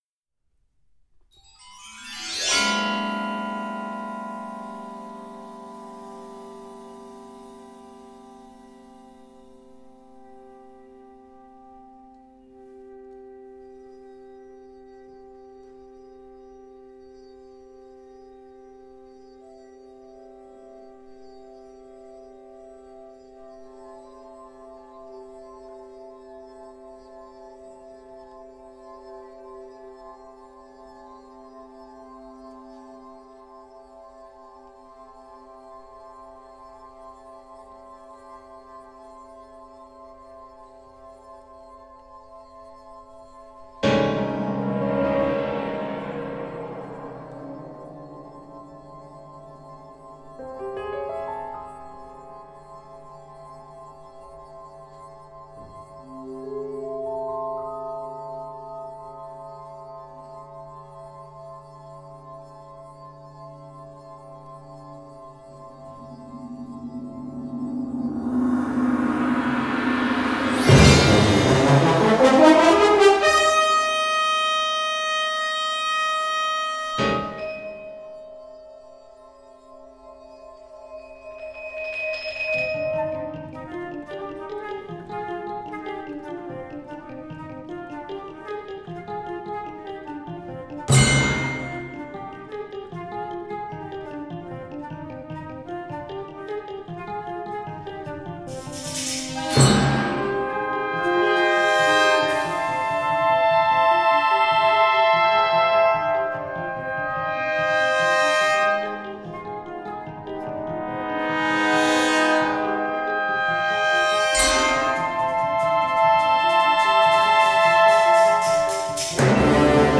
Partitions pour orchestre d’harmonie.
• View File Orchestre d'Harmonie